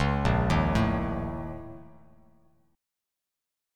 A#mM7bb5 chord